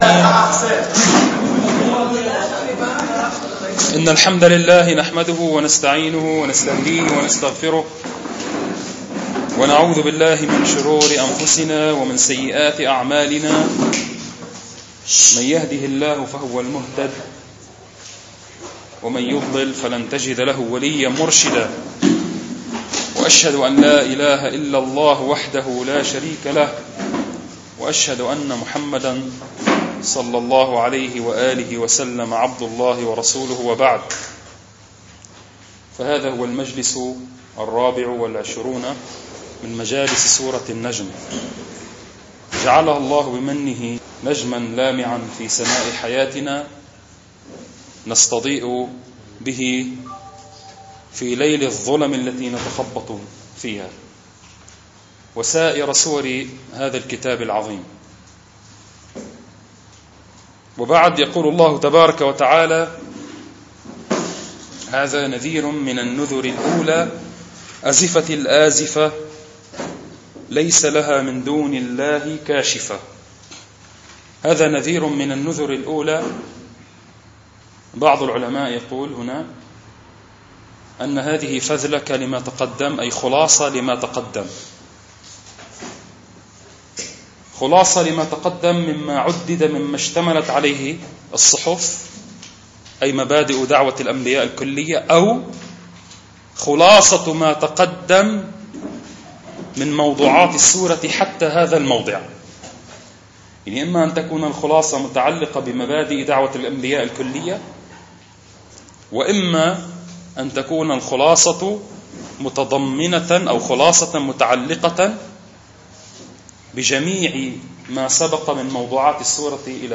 المكان : مدرسة المنار الإسلامية